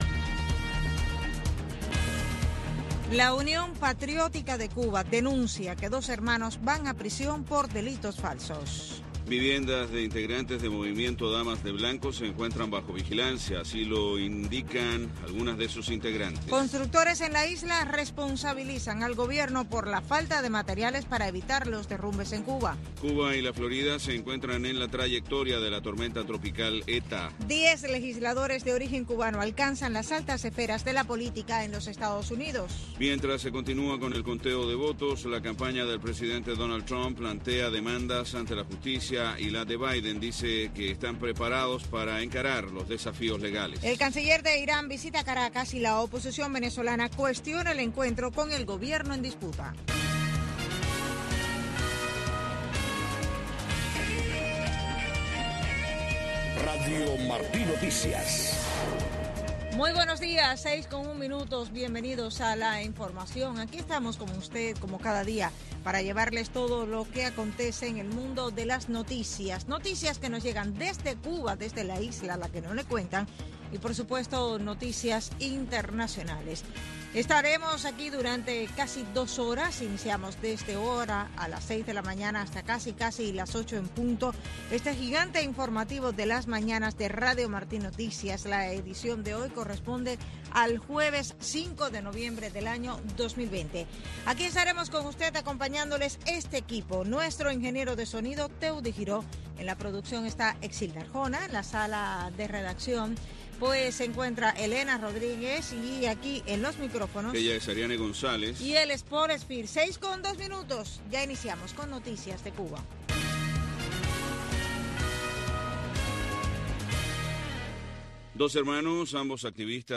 Noticiero de Radio Martí 6:00 AM